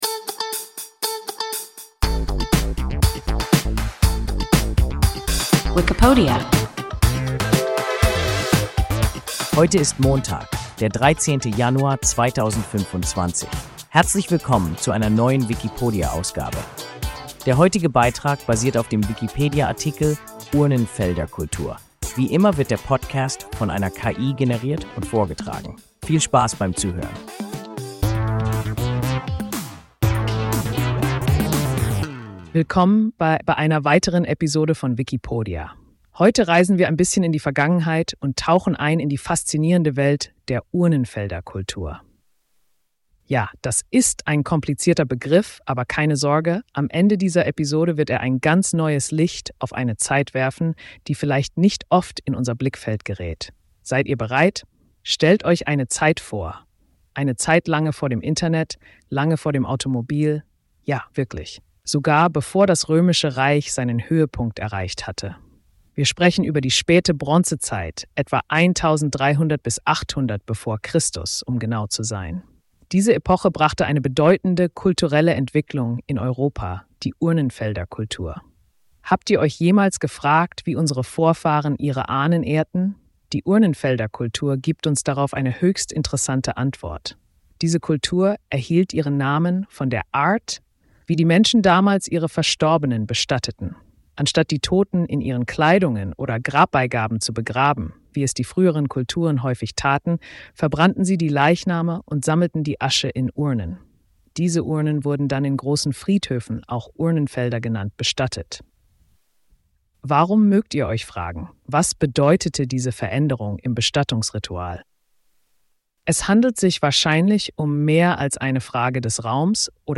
Urnenfelderkultur – WIKIPODIA – ein KI Podcast